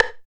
39 STICK  -R.wav